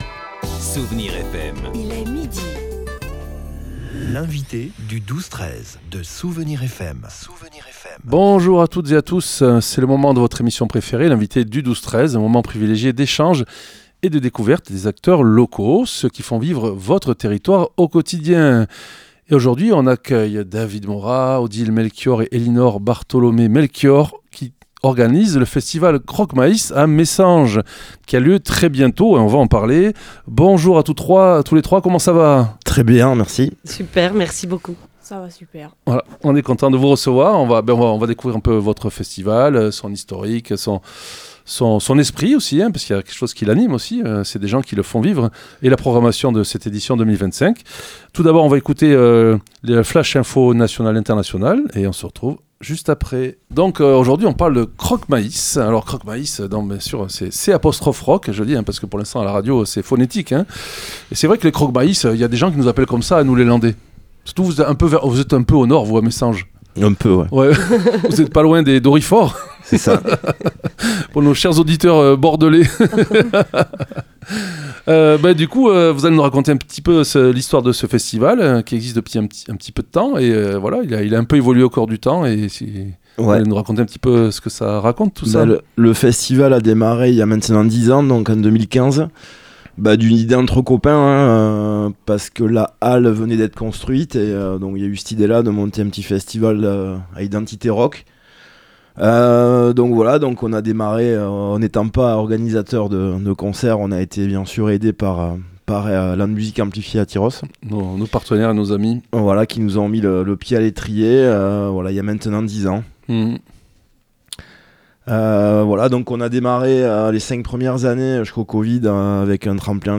L'invité(e) du 12-13 de Soustons